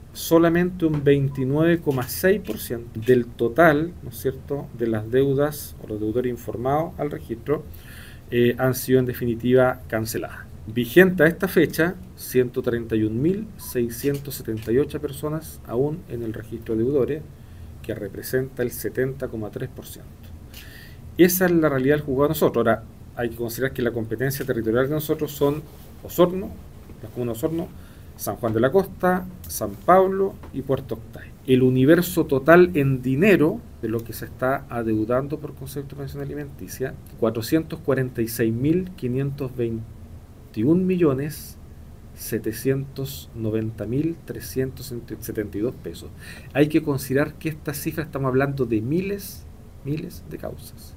El Magistrado Rause enfatizó que a pesar de esto, actualmente el 70,3% de las personas inscritas aún se mantienen en el registro, lo cual equivale a una deuda superior a 446 millones de pesos.